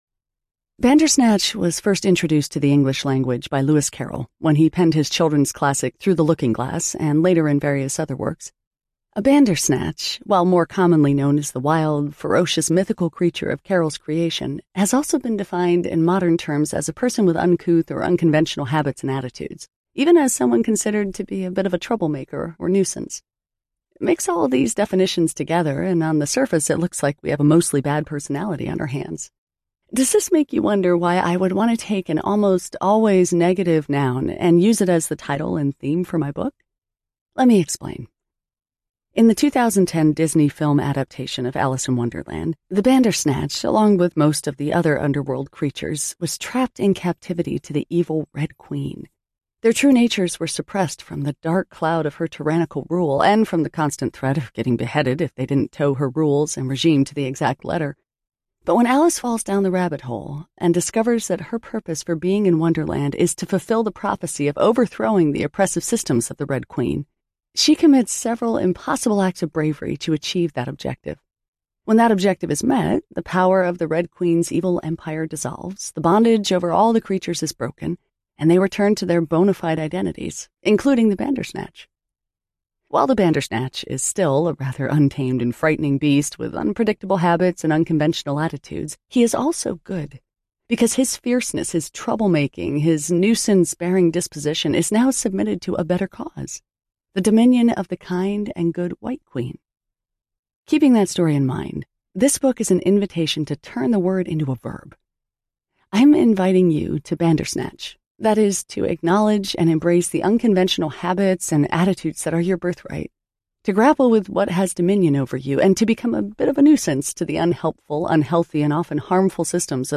Bandersnatch Audiobook
6.0 Hrs. – Unabridged